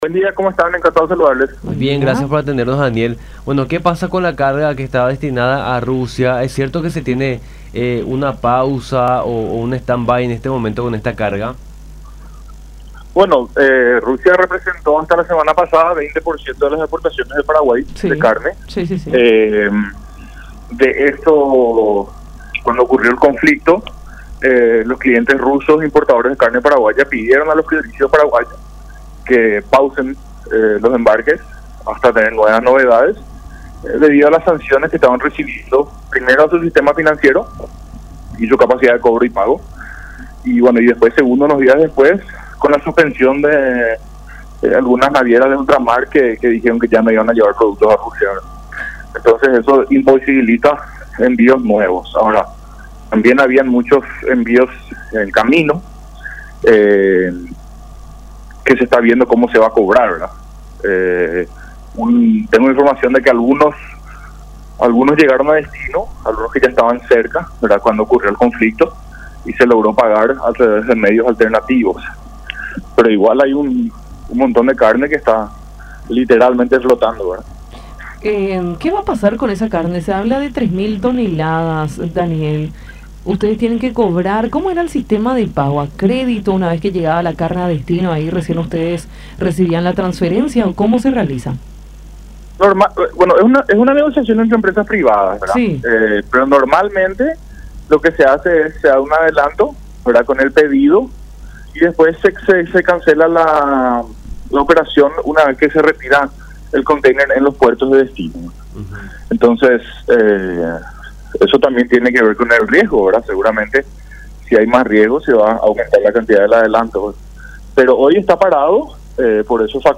en conversación con Nuestra Mañana a través de La Unión